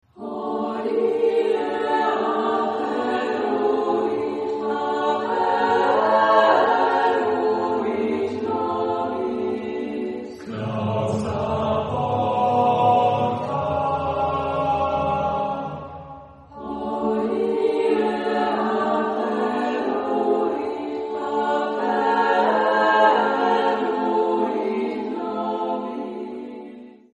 Epoque: 20th century
Genre-Style-Form: Sacred ; Middle ages ; Motet
Type of Choir: SSAATTBB  (8 mixed voices )